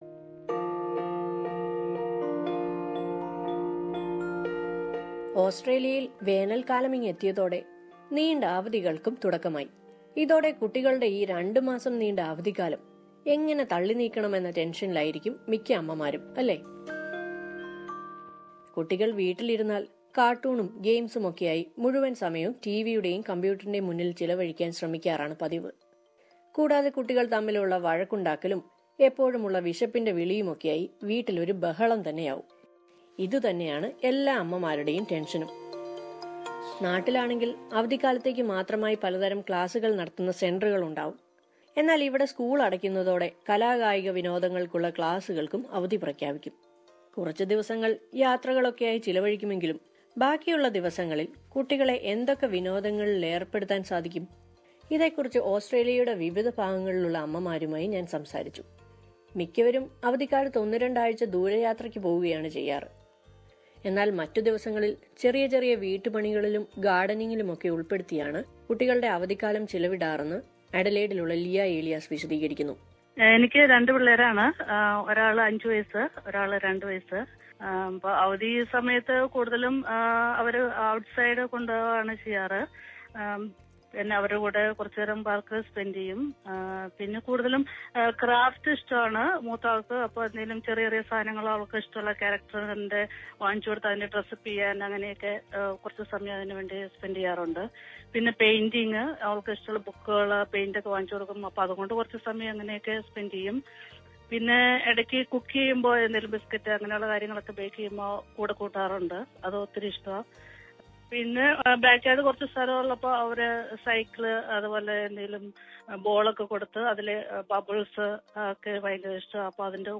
അവധഇക്കാലമെത്തുമ്പോള് മിക്ക മാതാപിതാക്കളുടെയും ഒരു ആശങ്കയാണ് കുട്ടികളെ എങ്ങനെ നോക്കും എന്നത്. ജോലിക്കു പോകാതെ കുട്ടികളെ നോക്കുന്നത് മാത്രമല്ല പ്രശ്നം, പലപ്പോഴും കുട്ടികതള്ക്ക് ഇഷ്ടമുള്ള വിനോദങ്ങളൊന്നും ലഭ്യമാകാറില്ല. എങ്ങനെയാണ് അവധിക്കാലത്ത് കുട്ടികളുടെ സമയം ചെലവഴിക്കുന്നതെന്ന അനുഭവം വിവരിക്കുകയാണ് ഓസ്ട്രേലിയയിലെ ചില മലയാളി അമ്മമാര്